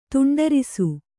♪ tuṇḍarisu